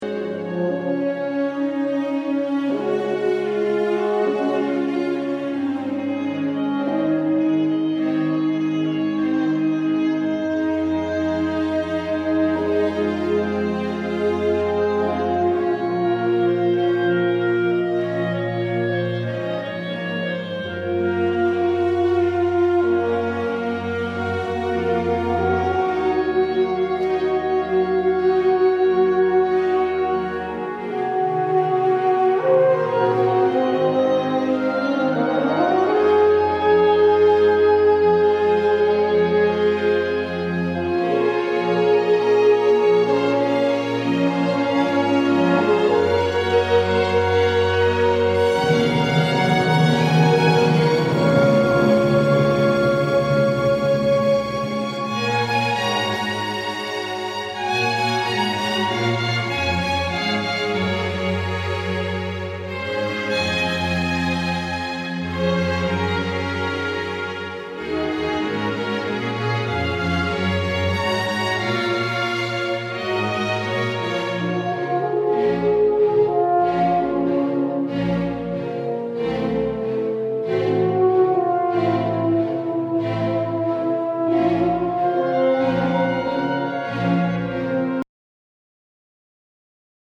Concerto for Euphonium and Loopstation. Recorded live at Christ Church, Skipton on 8 November 2014